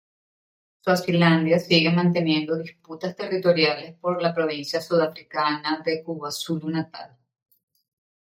Pronounced as (IPA) /ˈsiɡe/